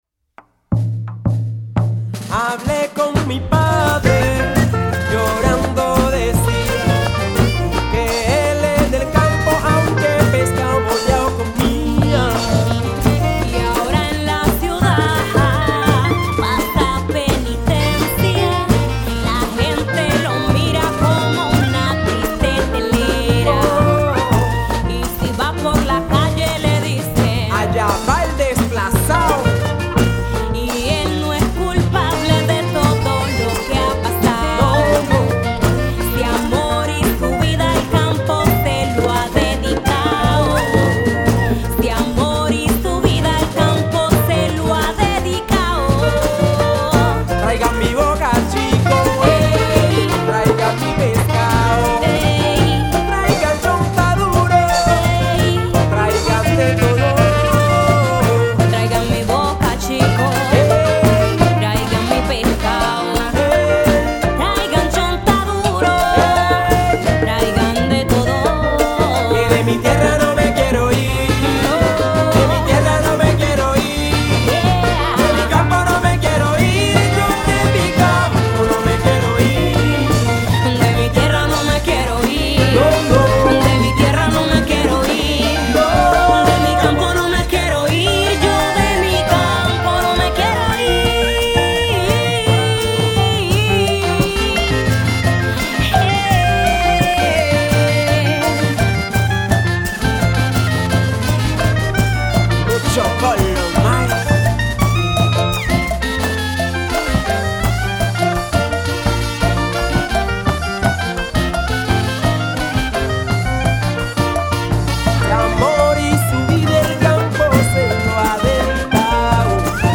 Canción
voz principal.
redoblante y coros.
tambora y clarinete.
platillo.
bajo y bongoes.
trompeta y campana.
piano.